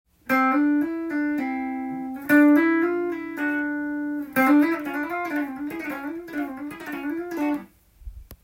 指がなまらないギターフレーズ集TAB譜
譜面通り弾いてみました